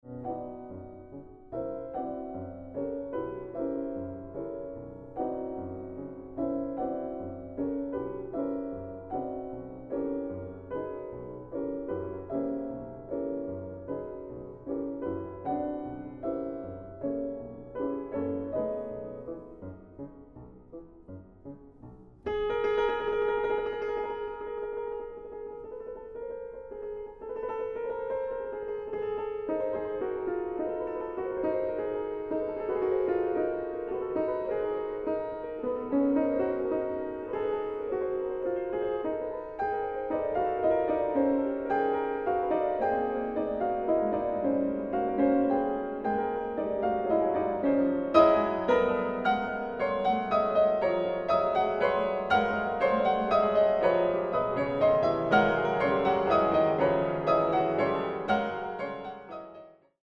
Klavier
Aufnahme: Mendelssohnsaal, Gewandhaus Leipzig